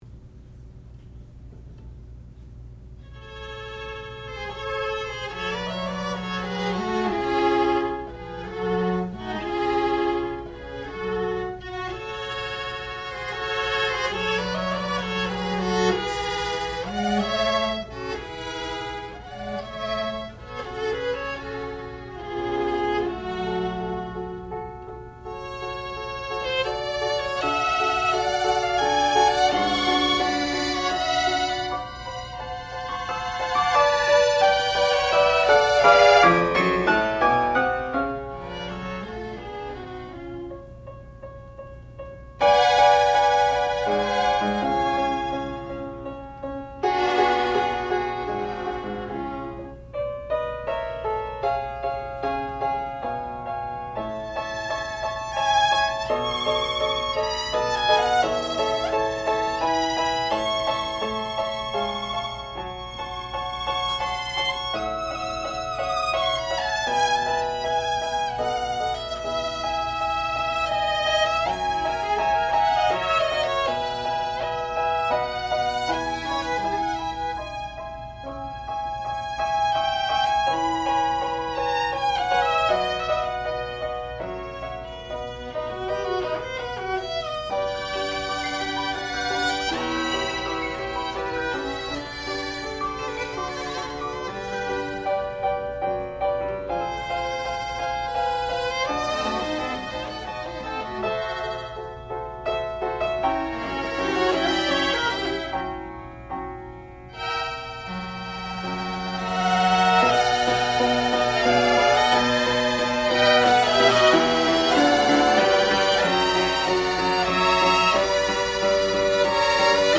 sound recording-musical
classical music
violoncello